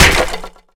smallLogCut.wav